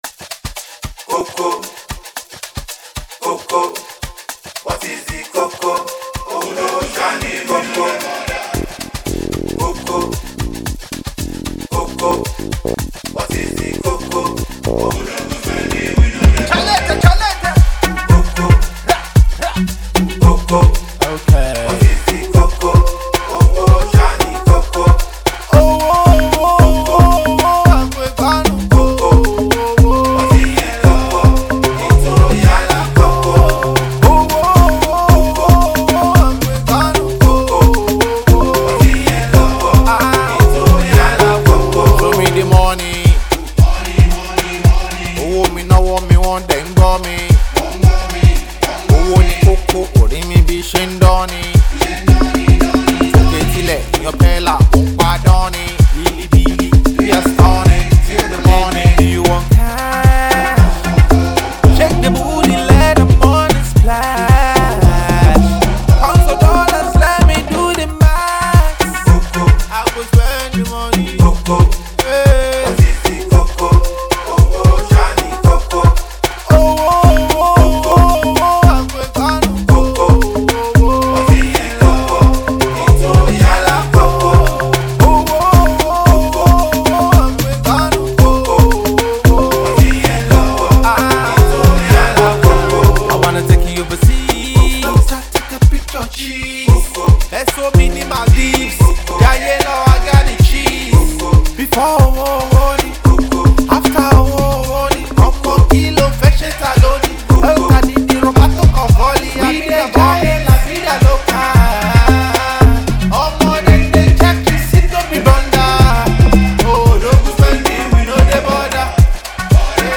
a dynamic fusion of catchy hooks and layered production